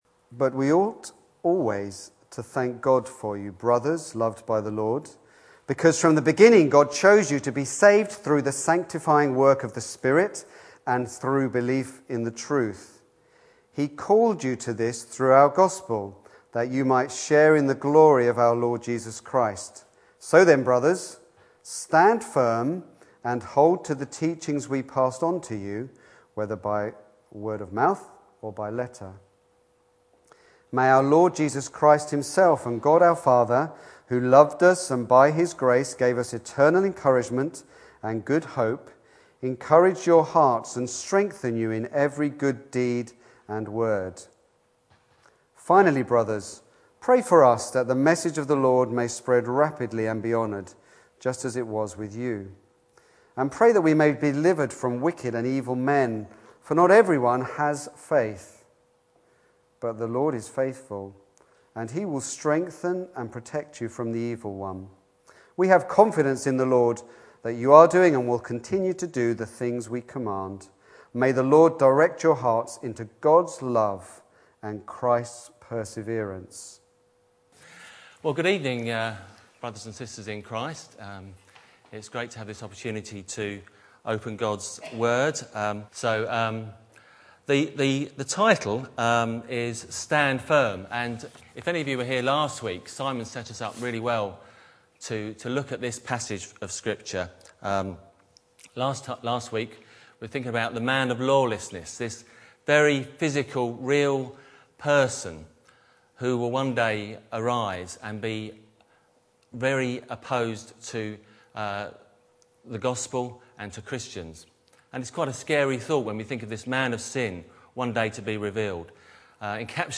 Back to Sermons